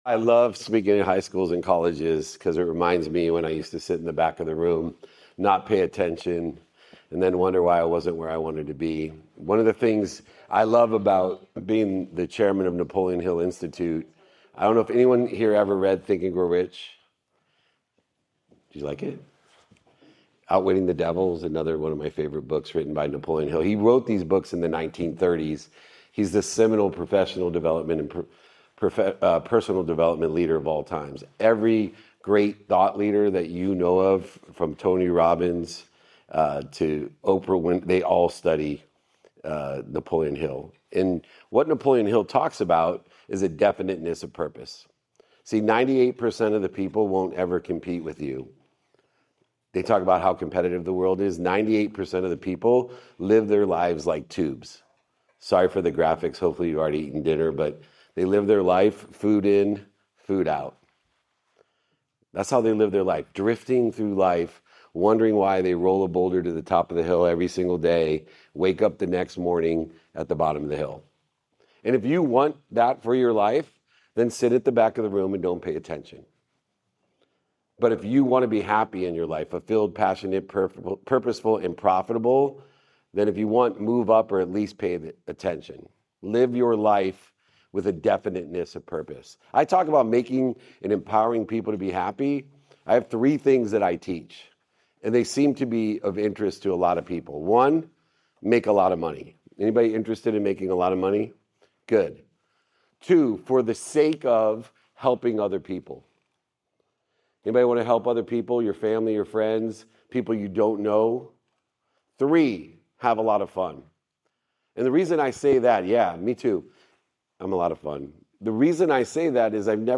In today’s episode, I speak to a room of young entrepreneurs about why definiteness of purpose matters more than raw talent, and how to use it to pitch with integrity. I share the five dollar for twenty dollar lesson, the five elements of every great pitch, and why saying “I don’t know” builds more trust than any slick deck. I explain my thousand step philosophy of success, the three no rule for protecting your time, and the real role of AI as a servant, not a threat.